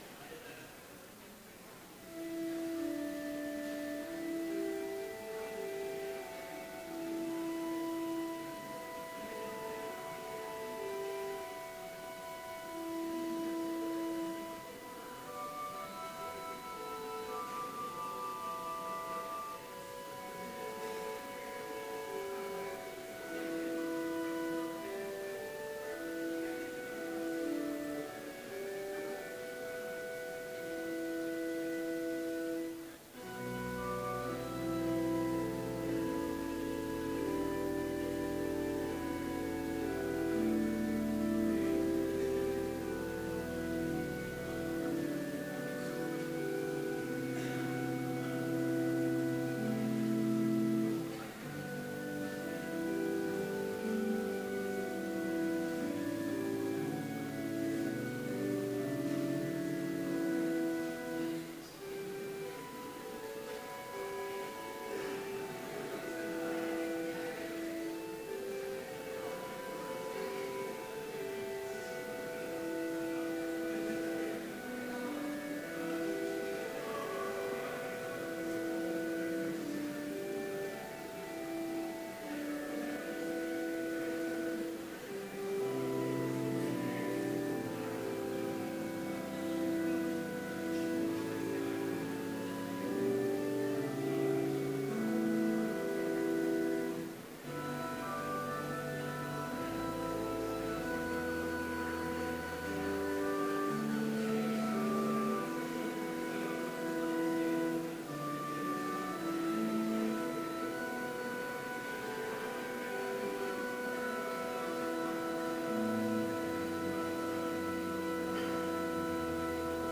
Complete service audio for Chapel - March 31, 2016